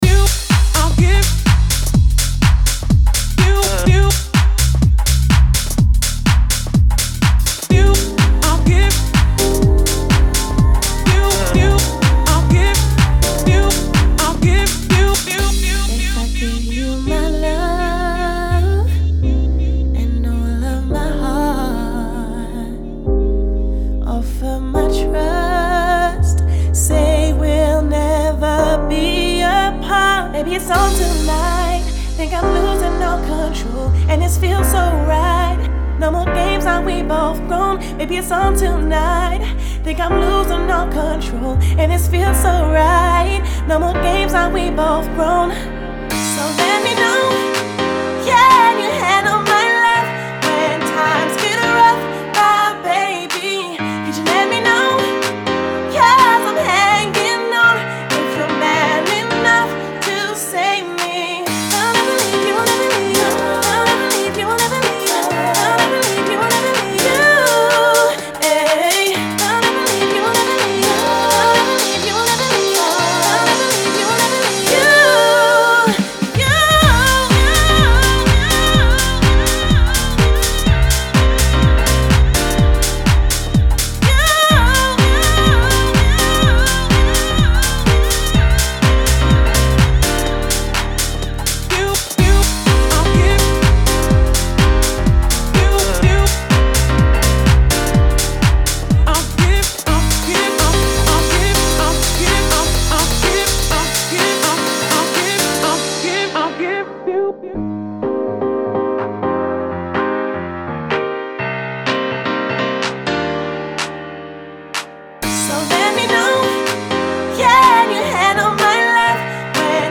Genre : Deep House